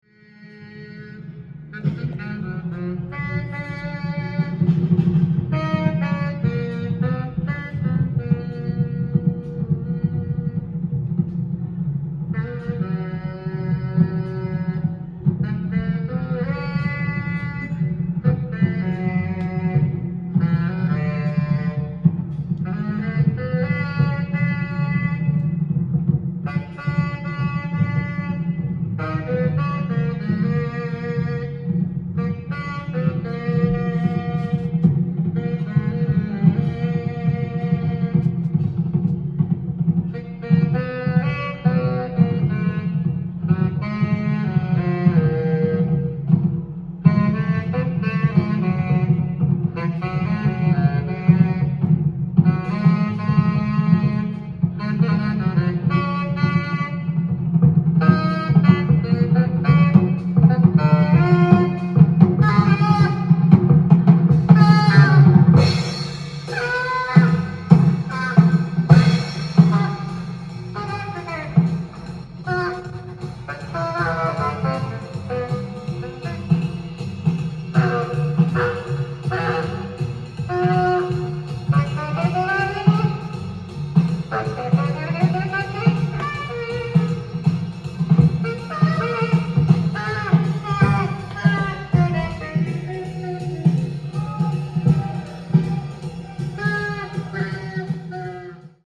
店頭で録音した音源の為、多少の外部音や音質の悪さはございますが、サンプルとしてご視聴ください。
孤独と絶望を経た魂の歌声。
音が稀にチリ・プツ出る程度